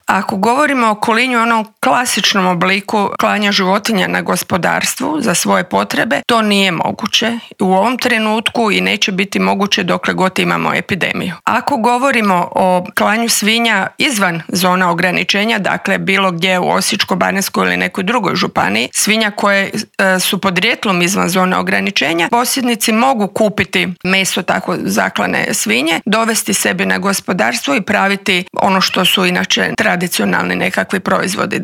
Socio-ekonomske posljedice su ogromne, a o tome kako izaći na kraj s ovom bolesti koja ne pogađa ljude u medicinskom, ali definitivno da u ekonomskom smislu, razgovarali smo u Intervjuu tjedna Media servisa s ravnateljicom Uprave za veterinarstvo i sigurnost hrane Tatjanom Karačić.